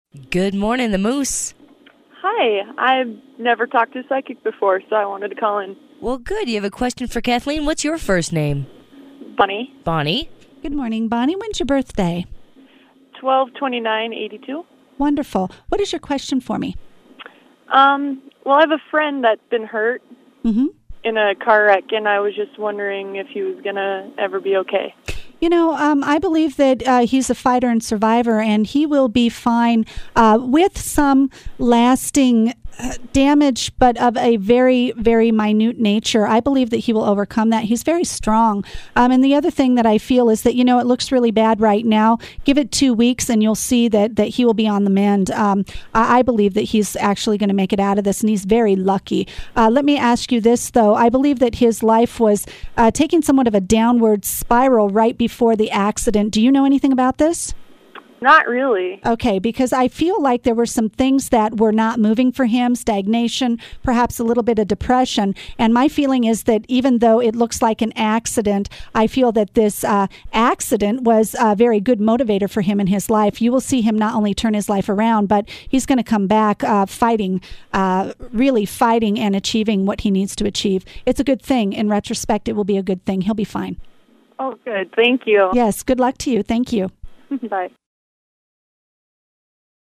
Live in the studio on KMMS 95.1 FM “The Moose” in Bozeman, Montana